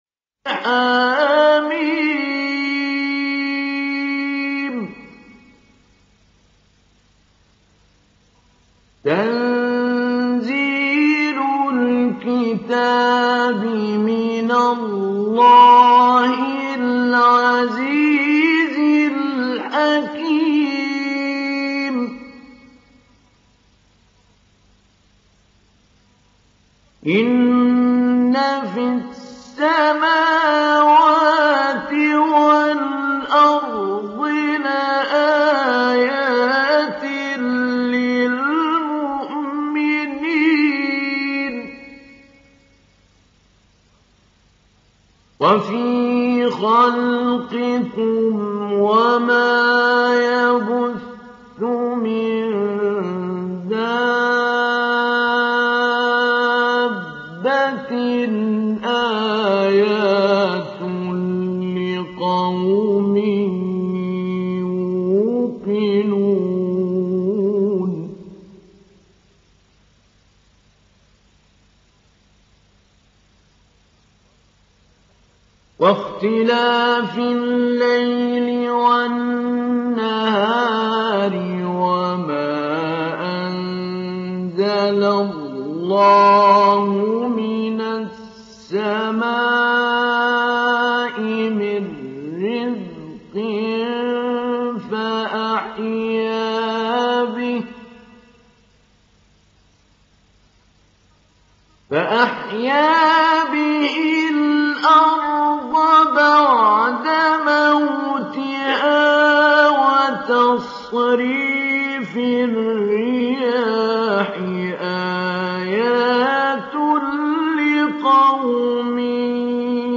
دانلود سوره الجاثيه mp3 محمود علي البنا مجود روایت حفص از عاصم, قرآن را دانلود کنید و گوش کن mp3 ، لینک مستقیم کامل
دانلود سوره الجاثيه محمود علي البنا مجود